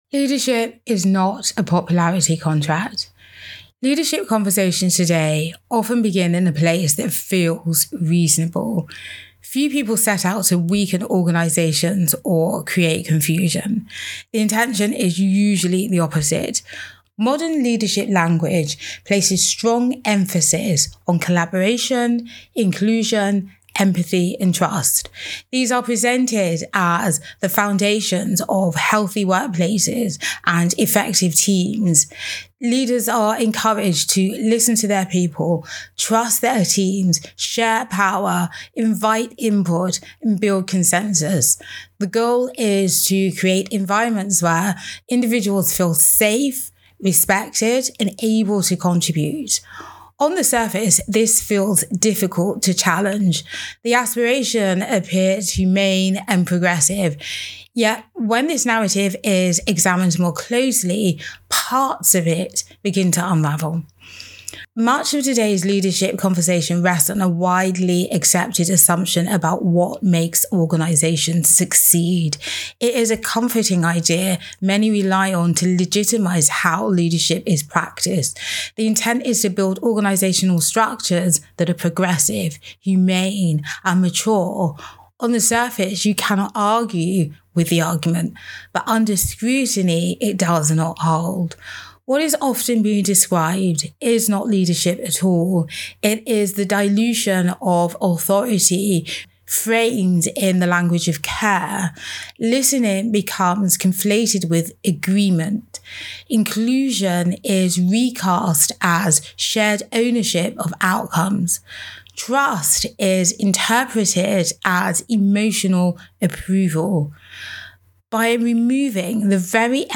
Audio Commentary An audio commentary on leadership decision making, decision ownership, and the organisational cost of approval-seeking leadership.